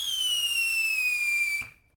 whistle.ogg